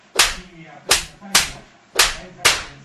varios cachetazos